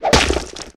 tentackle.ogg